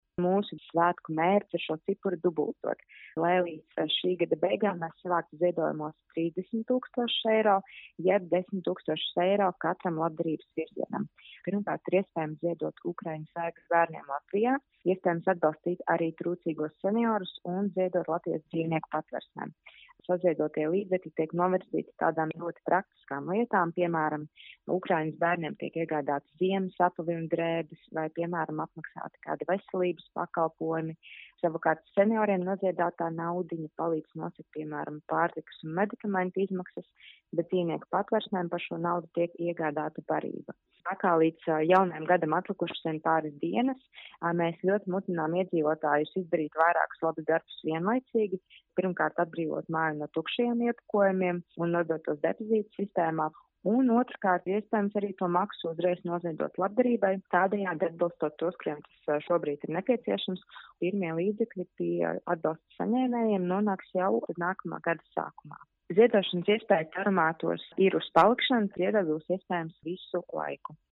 RADIO SKONTO Ziņās par iespēju ziedot depozītmaksu